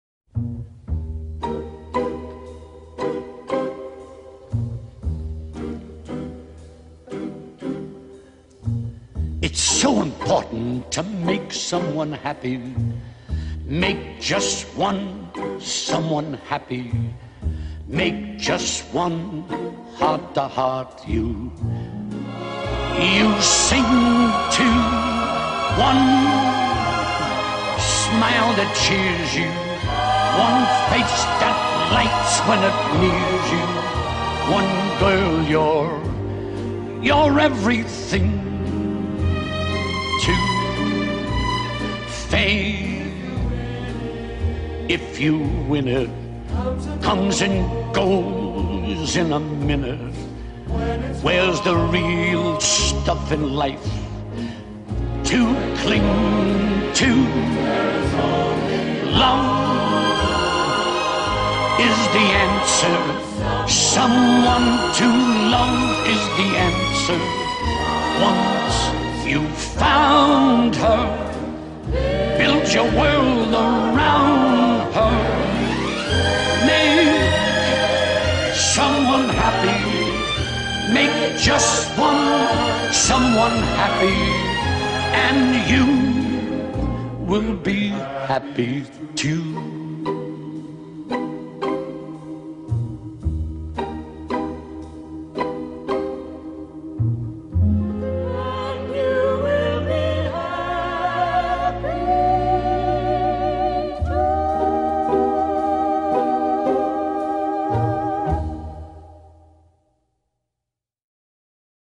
A beloved raspy throated entertainer